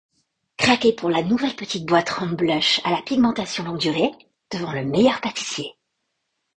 Voix off
28 - 44 ans - Mezzo-soprano